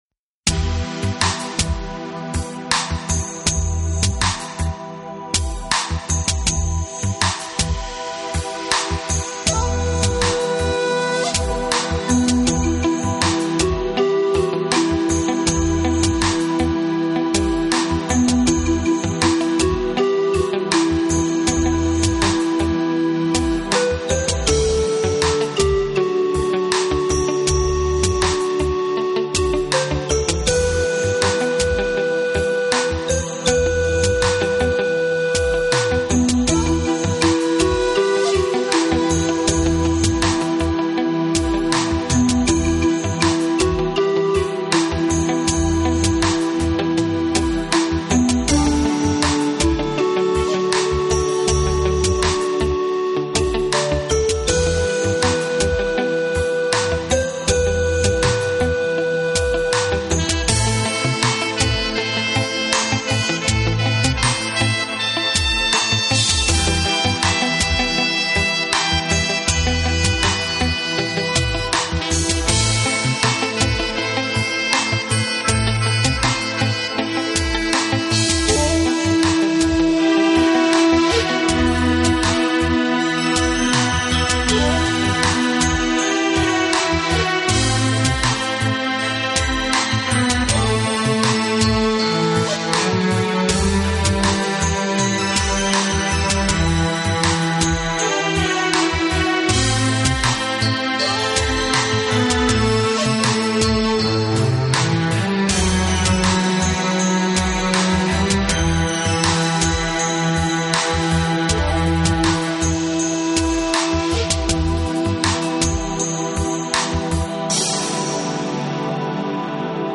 新世纪音乐
和整体相对"happy"的曲调。